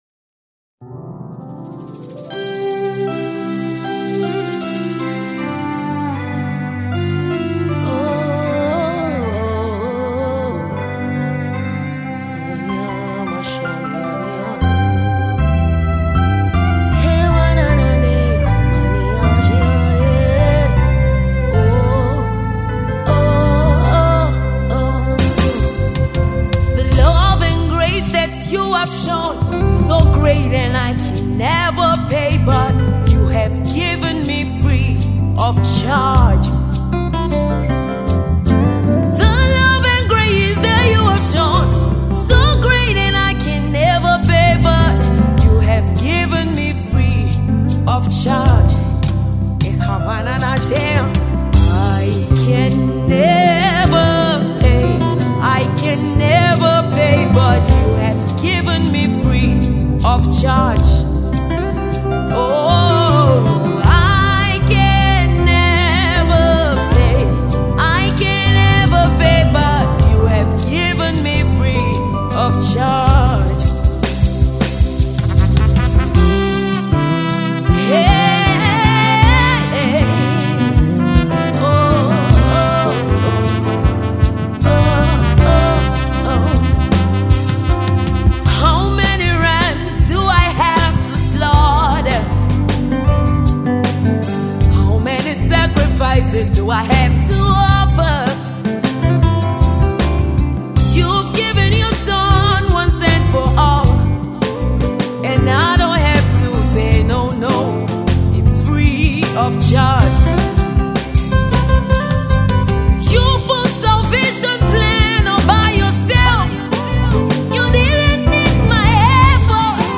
the sensational gospel songstress released her first project